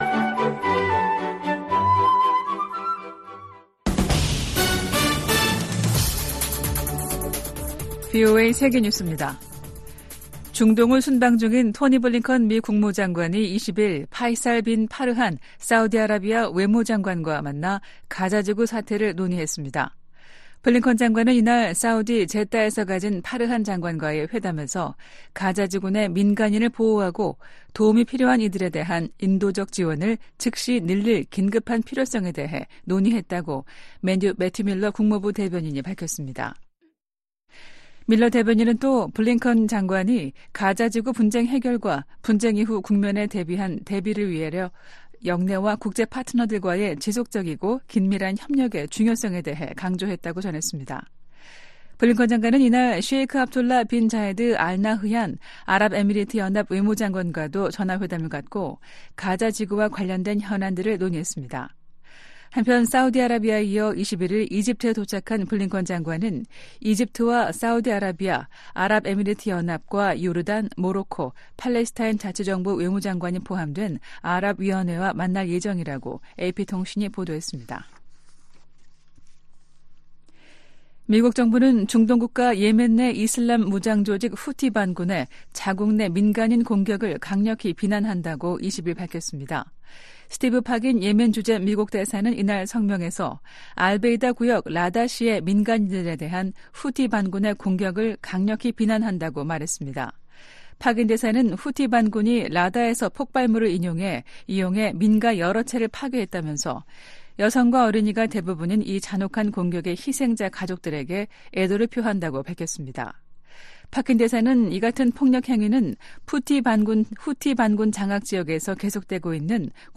VOA 한국어 아침 뉴스 프로그램 '워싱턴 뉴스 광장' 2024년 3월 22일 방송입니다. 북한이 영변 핵시설에서 핵탄두 소형화에 필수적인 삼중수소 생산 시설을 가동 중이라는 위성사진 분석 결과가 나왔습니다. 북한이 러시아와 관계를 강화하면서 국제 질서를 위협하고 있다고 주한미군사령관이 지적했습니다. 블라디미르 푸틴 러시아 대통령의 5연임이 확정되면서 북한과 러시아 밀착에 탄력이 붙을 것이란 전망이 나옵니다.